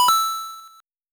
Sound effect of "Coin" from New Super Mario Bros.
NSMB_Coin.oga